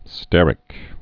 (stĕrĭk, stîr-) also ster·i·cal (-ĭ-kəl)